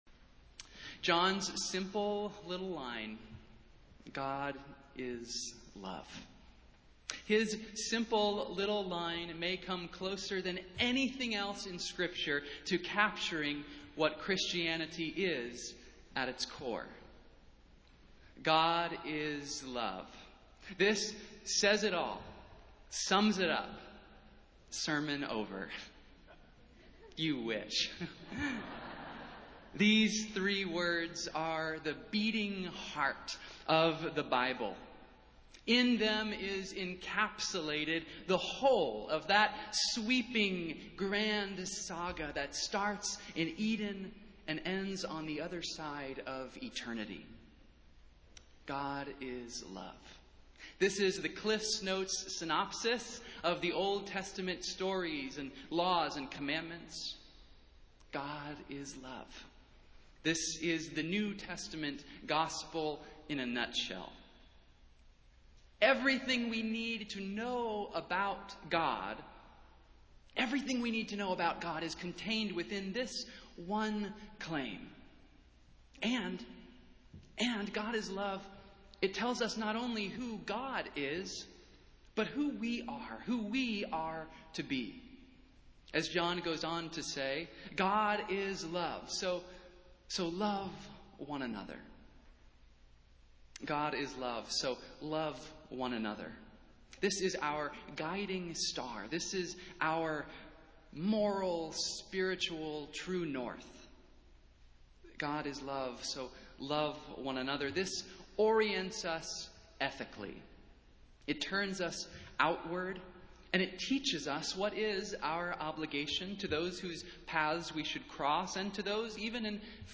Festival Worship - Trinity Sunday